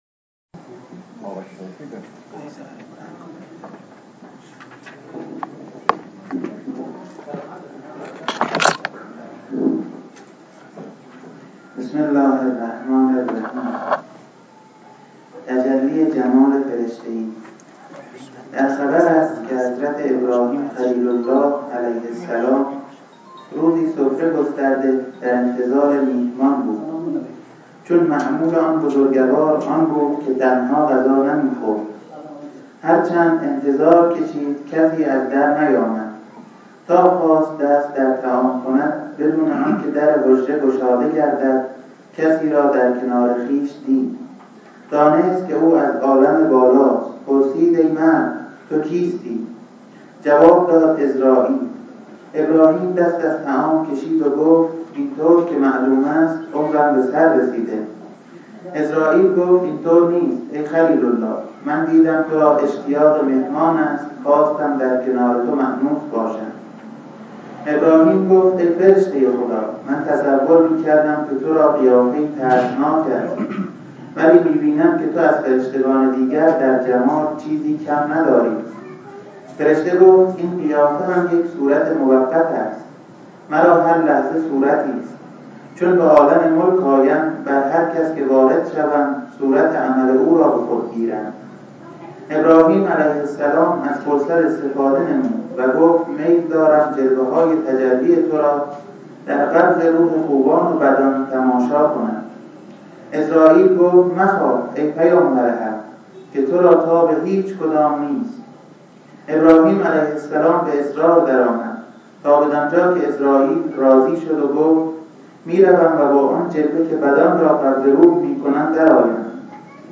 کتاب عبادت عاشقانه چاپ پنجم صفحه 252 در بحث اسماء الله دنیا به طلیعه ی خورشید می ماند، ولی آخرت ظهور تامۀ اسماء است. سخنرانی دوشنبه 8-7-92 دانلود